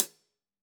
Index of /musicradar/Hi Hats/Zildjian K Hats
KHats Clsd-11.wav